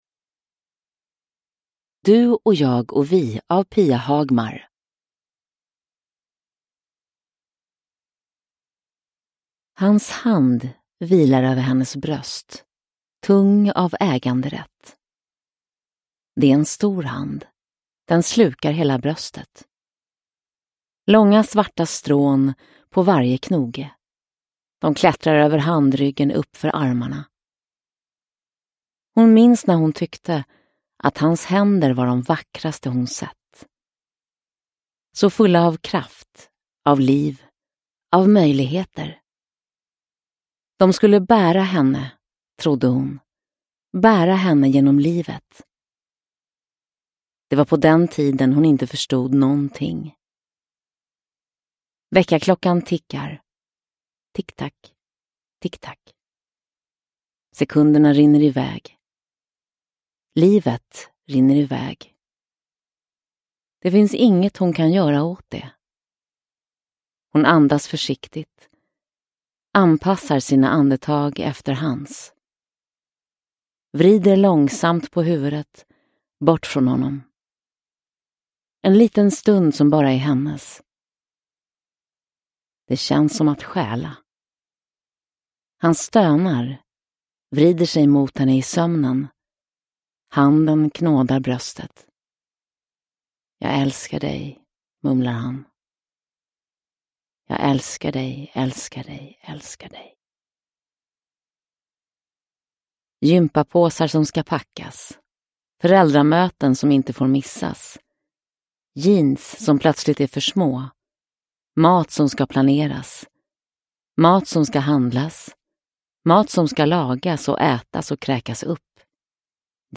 Du och jag och vi – Ljudbok – Laddas ner